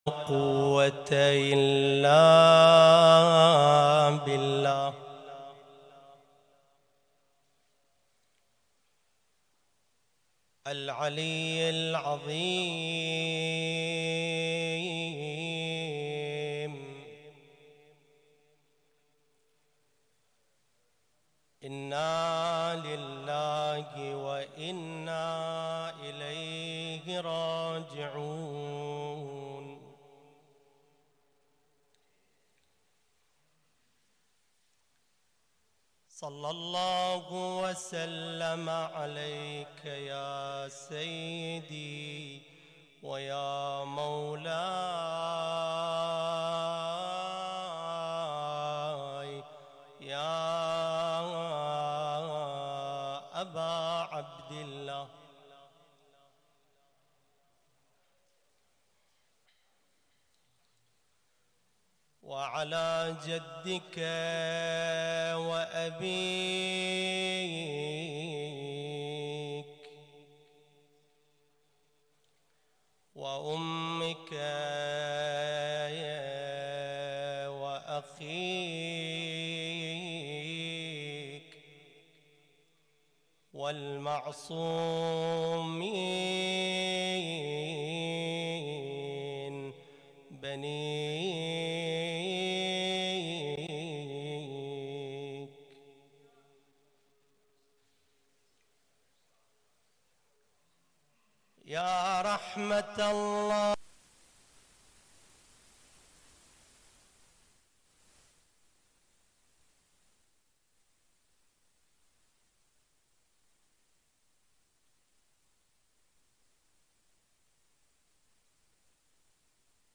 المجلس الحسيني ليلة 2 رمضان 1440هـ
محاضرة